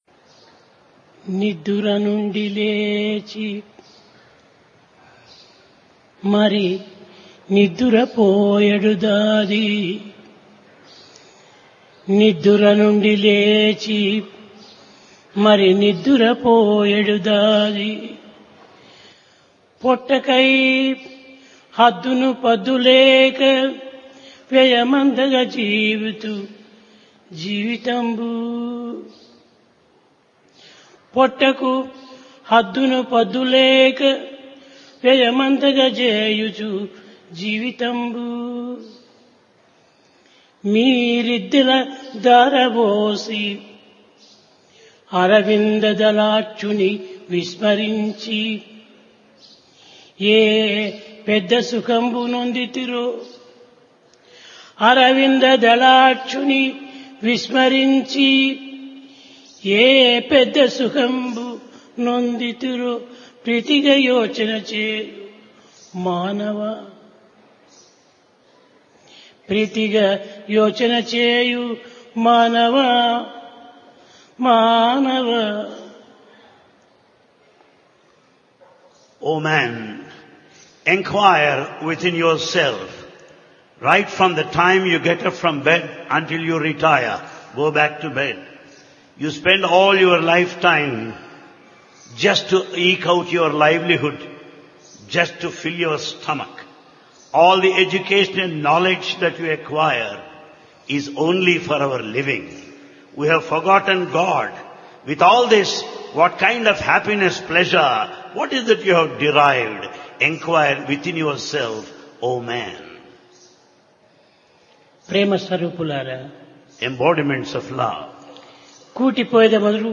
Discourse